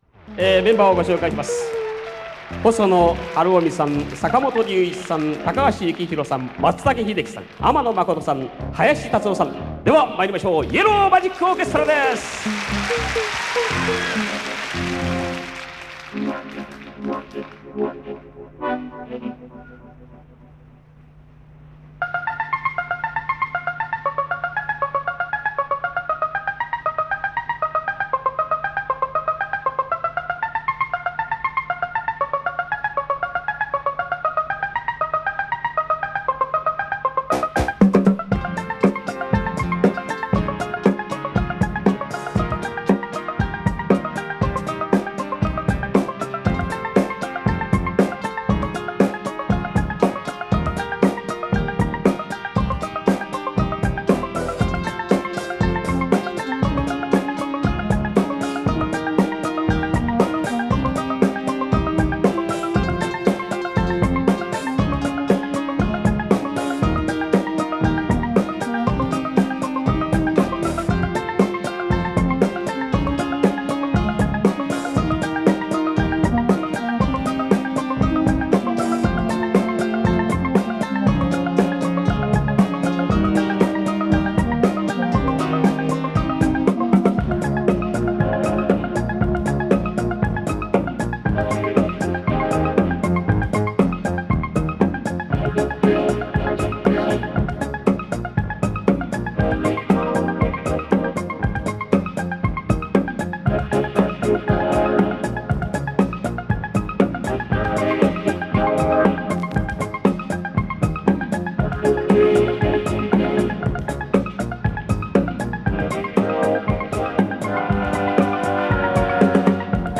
live at Shiba Yuki Chokin Hall, Tokyo